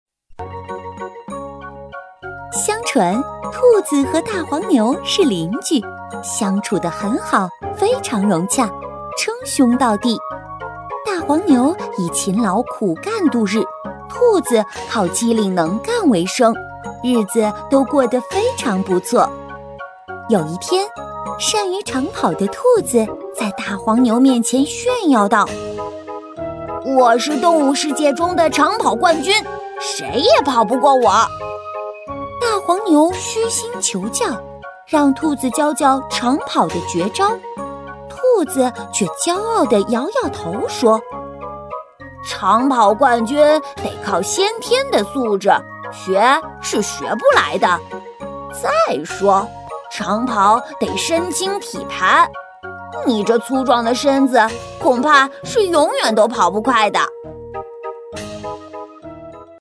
129女声样音试听
129女声儿童故事12生肖
129女声儿童故事12生肖.mp3